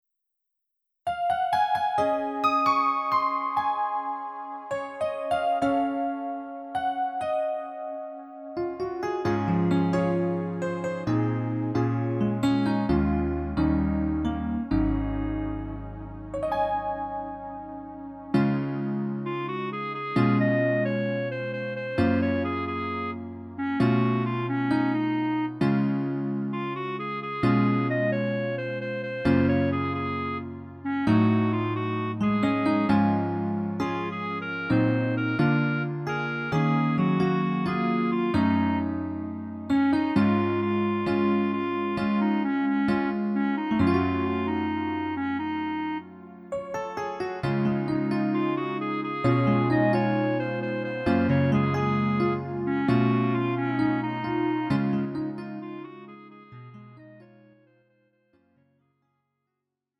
음정 원키 4:28
장르 가요 구분 Lite MR